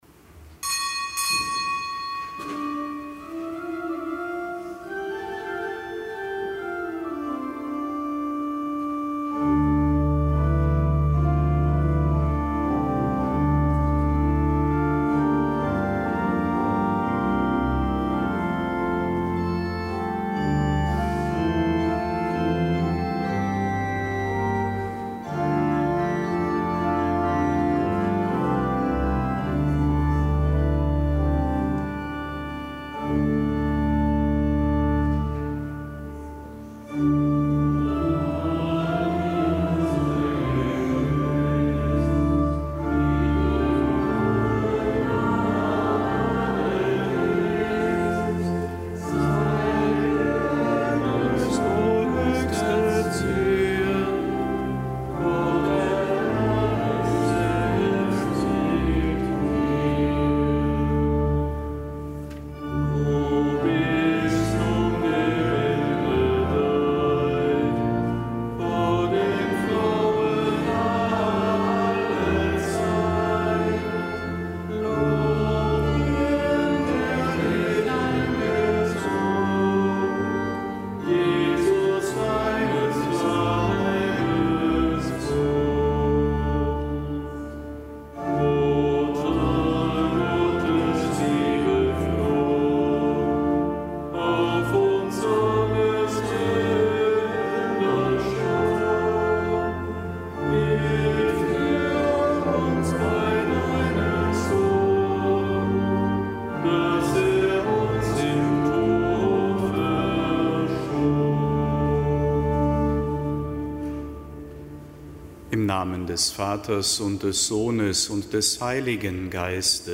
Kapitelsmesse am Samstag der vierzehnten Woche im Jahreskreis
Kapitelsmesse aus dem Kölner Dom am Samstag der vierzehnten Woche im Jahreskreis, Marien-Samstag.